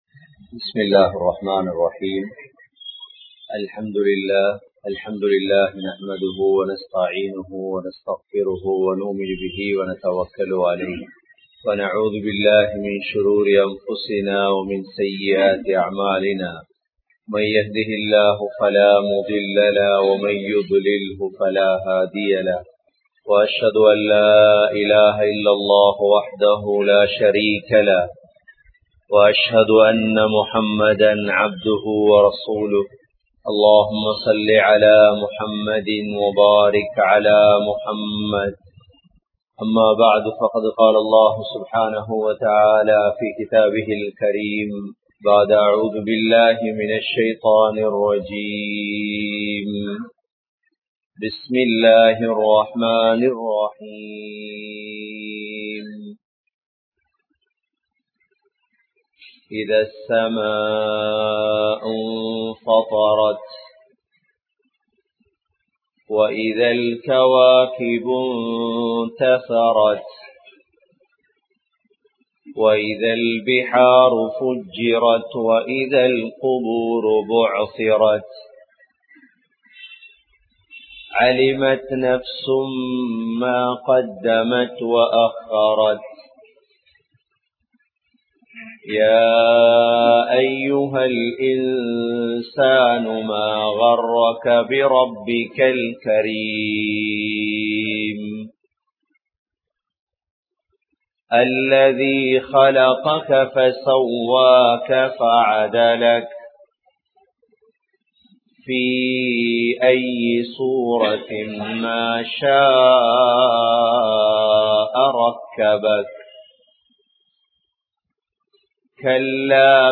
Deenudaiya Perumathi Eppothu Vilankum?(தீனுடைய பெறுமதி எப்போது விளங்கும்?) | Audio Bayans | All Ceylon Muslim Youth Community | Addalaichenai